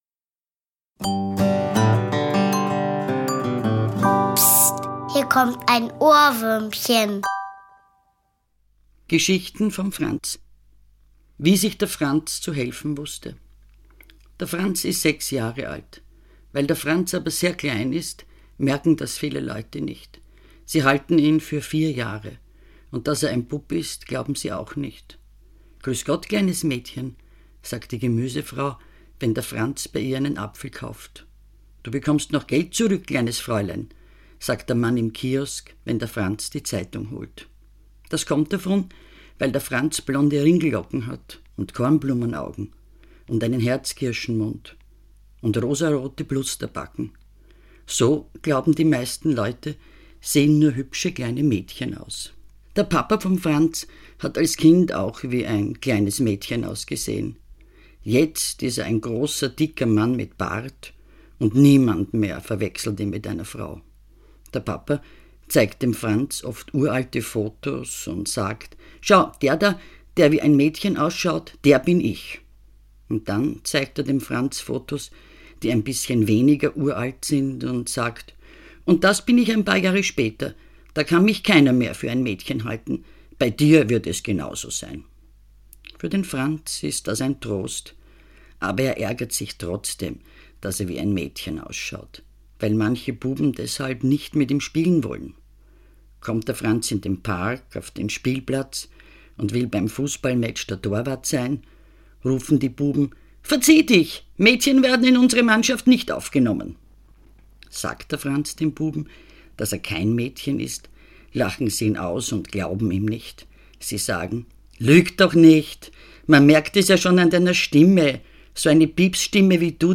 Christine Nöstlinger (Sprecher)
lt;p>Geschichten vom Franz, gelesen von Autorin Christine Nöstlinger.
Weil er aber blonde Ringellocken und rosarote Plusterbacken hat, wird er dauernd für ein Mädchen gehalten! Ihre Geschichten vom Franz hat die inzwischen verstorbene Christine Nöstlinger hier selbst eingelesen.